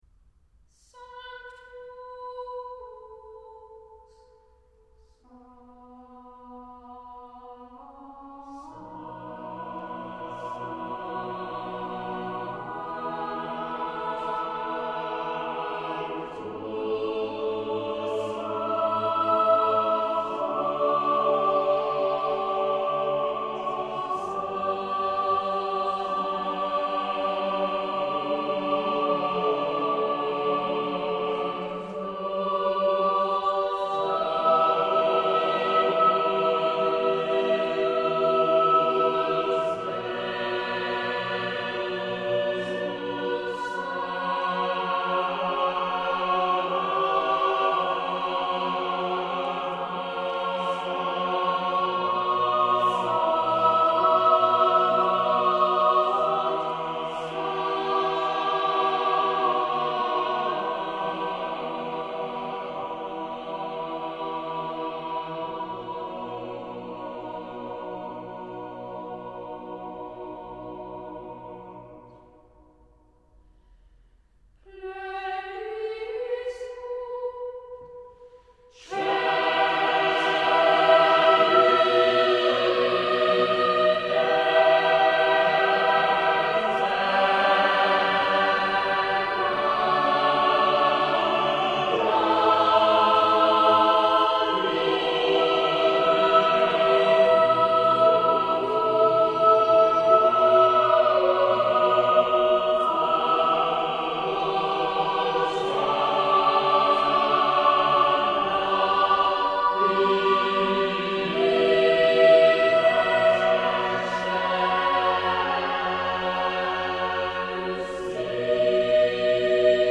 Eδώ υπάρχουν έξι γραμμές όμοιας φύσεως. Δεν είναι δυνατόν να τις ξεχωρίσουμε όλες.